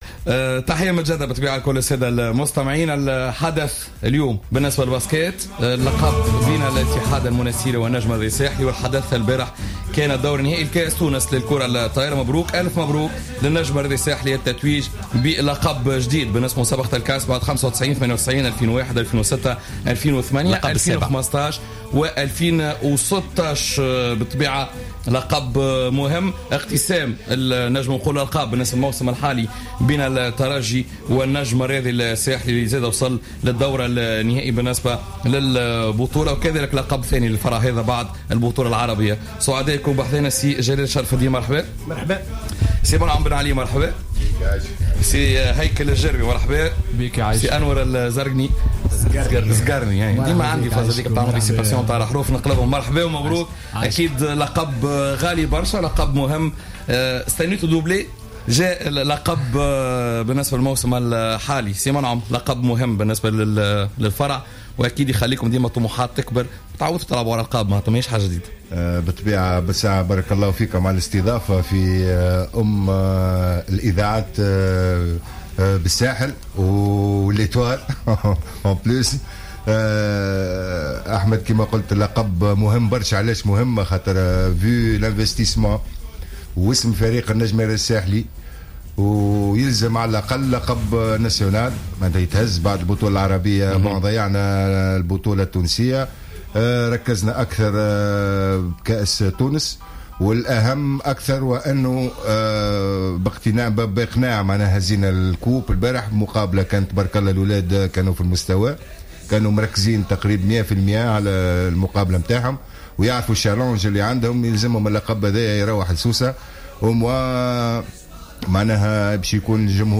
كأس تونس للكرة الطائرة في إستوديو جوهرة أف أم